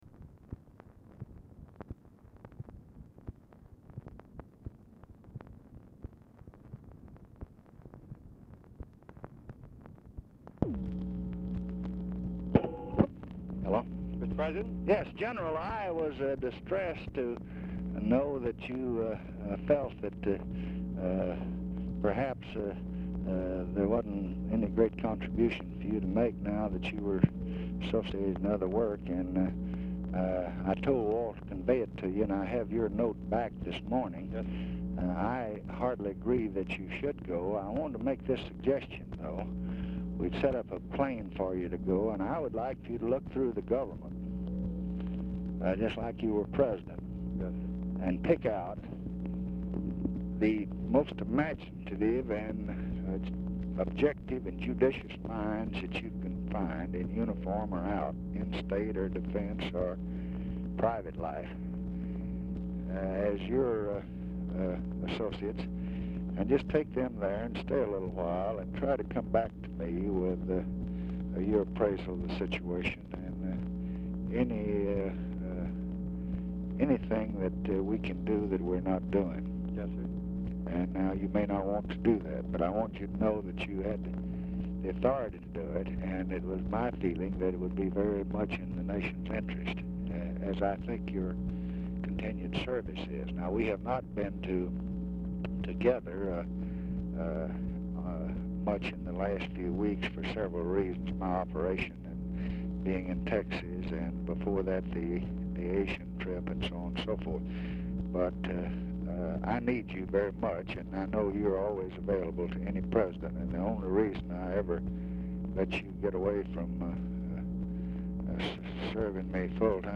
Telephone conversation # 11326, sound recording, LBJ and MAXWELL TAYLOR, 1/9/1967, 1:19PM | Discover LBJ
Format Dictation belt
Location Of Speaker 1 Oval Office or unknown location